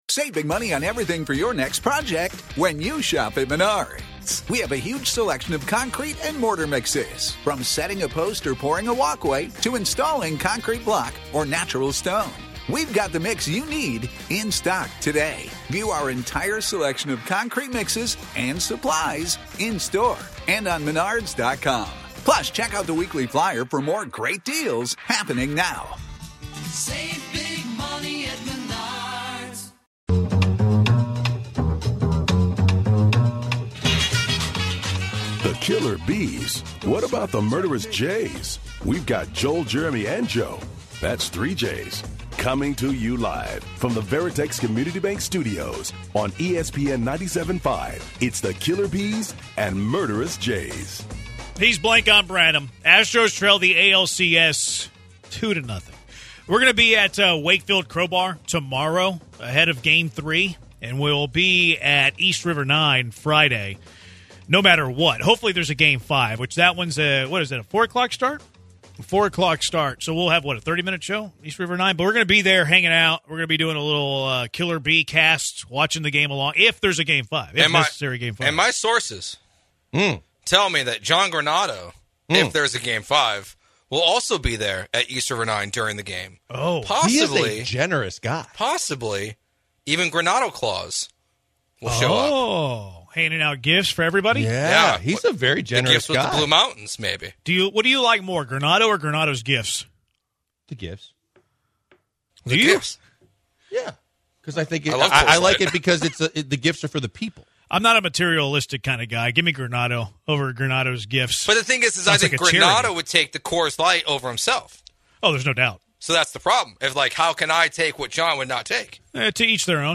The guys talk to listeners of the show discussing what adjustments the Astros can make to win games 3 and 4 of the ALCS.